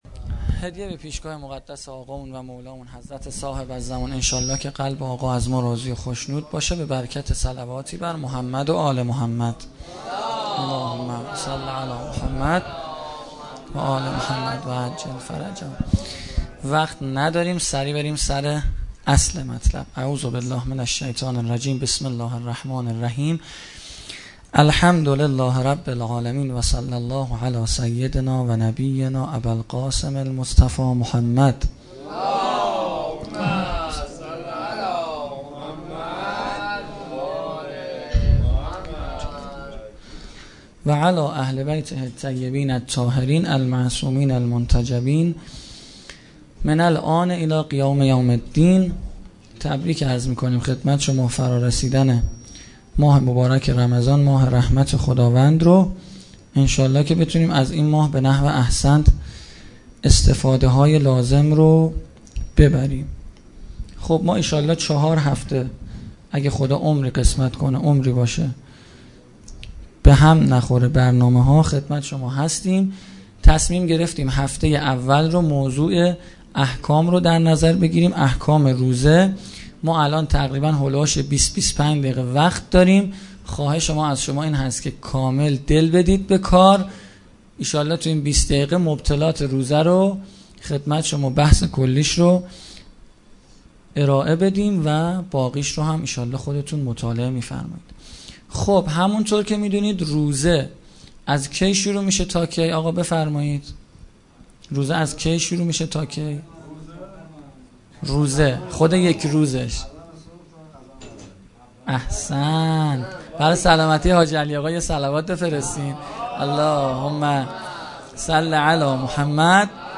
صوت کامل شب ششم ماه مبارک رمضان